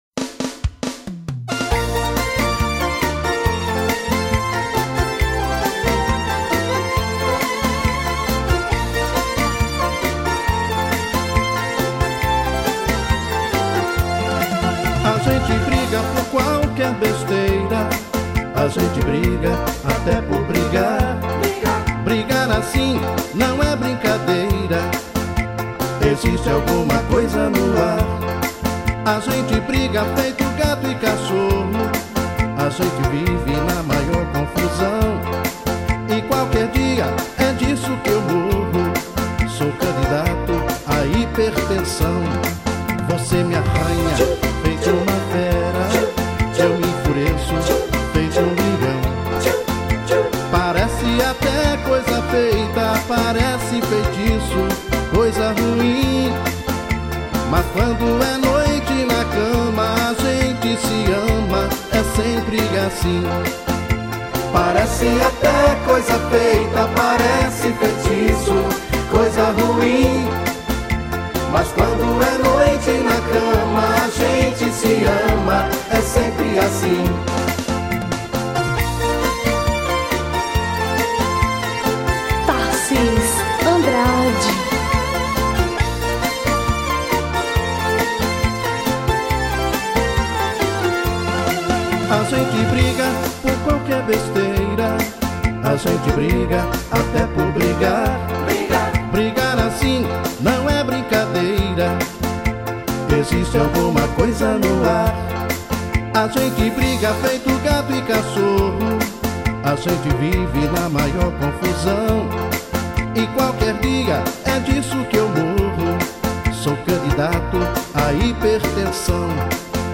EstiloPop Rock